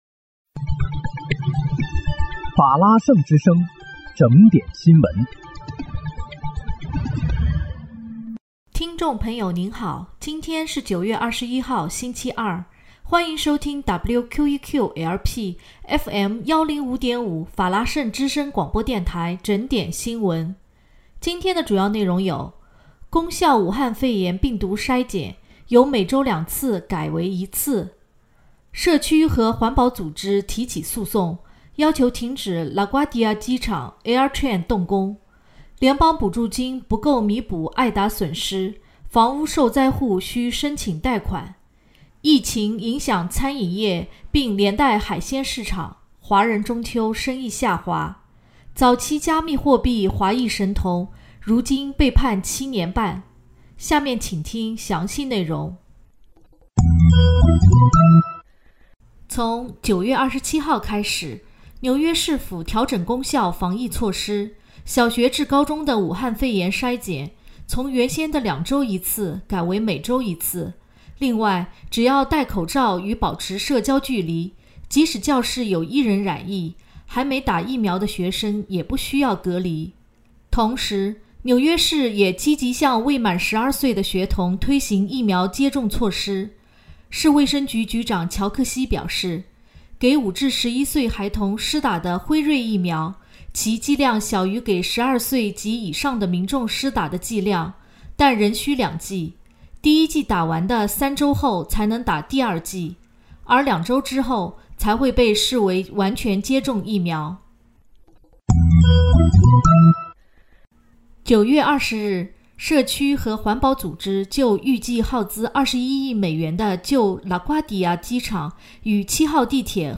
9月21日（星期二）纽约整点新闻